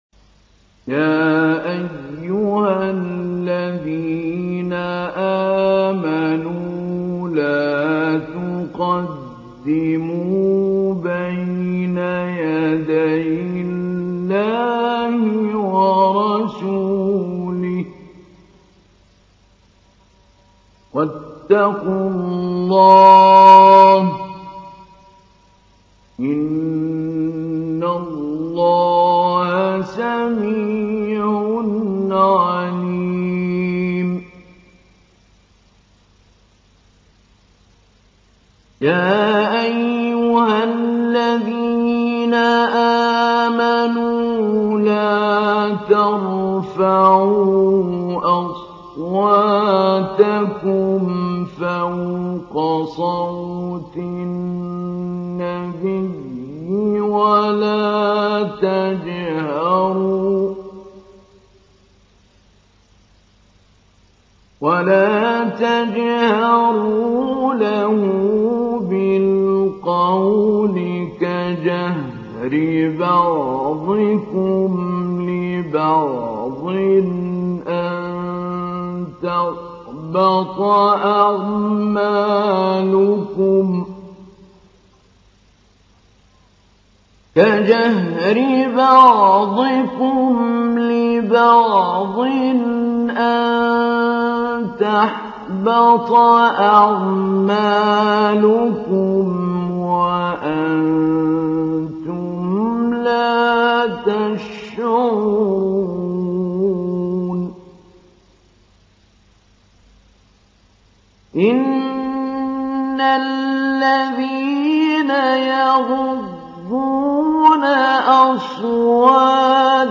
تحميل : 49. سورة الحجرات / القارئ محمود علي البنا / القرآن الكريم / موقع يا حسين